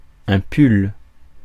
Ääntäminen
Synonyymit chandail pull-over chandail à manches longues Ääntäminen France: IPA: [pyl] Haettu sana löytyi näillä lähdekielillä: ranska Käännös 1. chaleco {m} Suku: m .